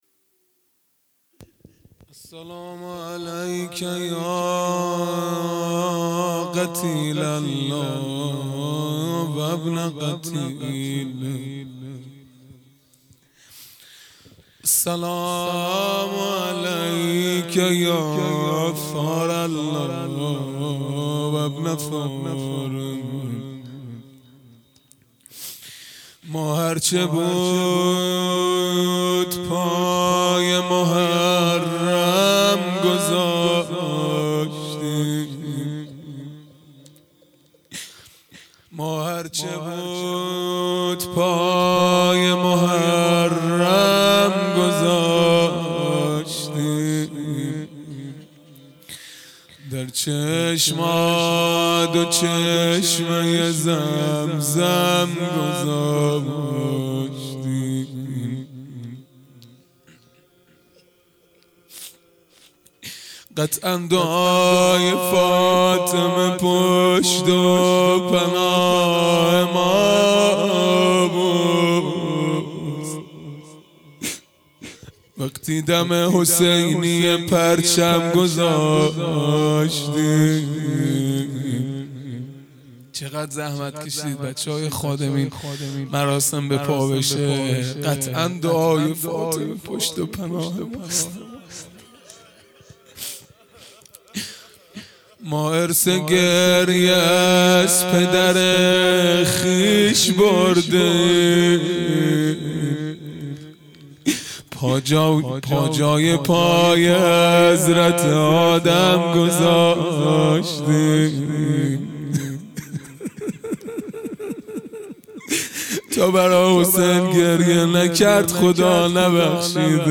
خیمه گاه - هیئت بچه های فاطمه (س) - روضه | ما هرچه بود پای محرم گذاشتیم | یک شنبه ۲۴ مرداد ۱۴۰۰
دهه اول محرم الحرام ۱۴۴۳ | شب هفتم